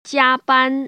[jiā//bān] 지아반  ▶